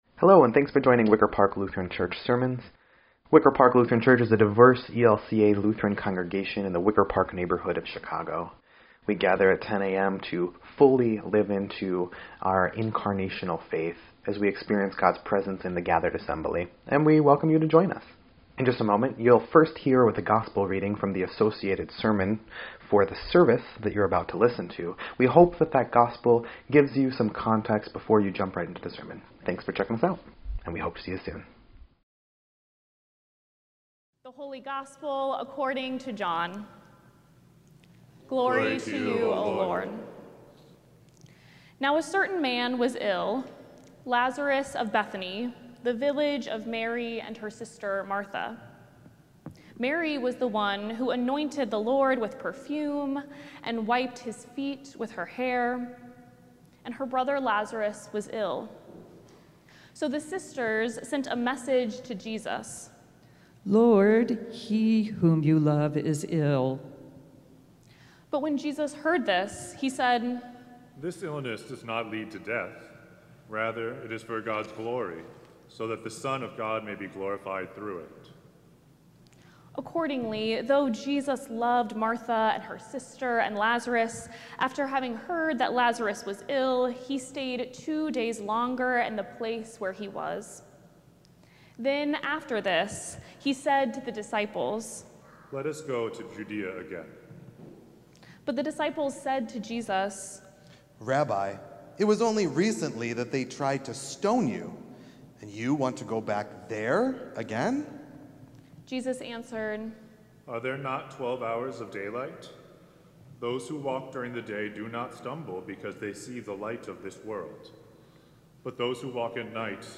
3.22.26-Sermon_EDIT.mp3